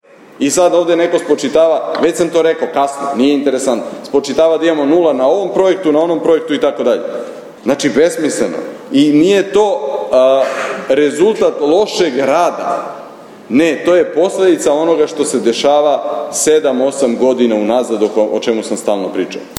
Na sednici Skupštine grada održanoj u sredu u Zaječaru odbornici su razmatrali Izveštaj o izvršenju budžeta za prvih devet meseci ove godine.
Stefan-Zankov-Skupstina-09.11.mp3